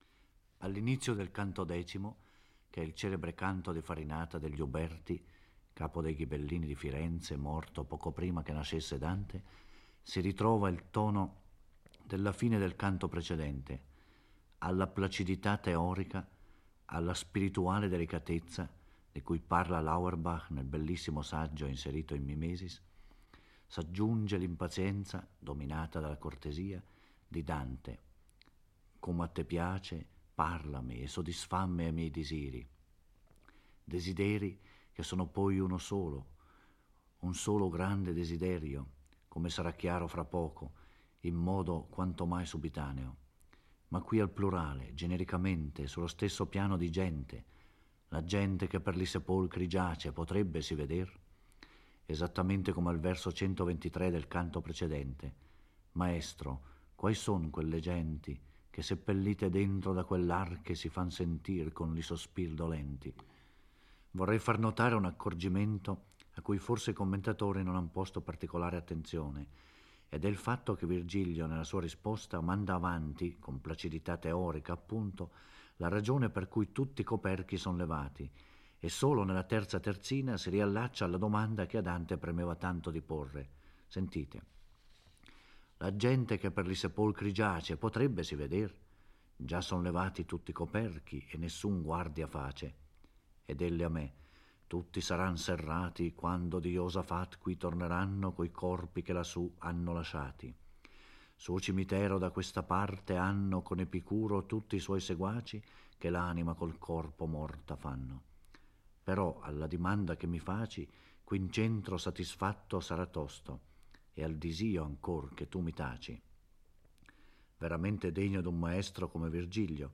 Giorgio Orelli legge e commenta il X canto dell'Inferno. Dante incontra Farinata degli Uberti, il più famoso capo ghibellino della Firenze del XIII secolo.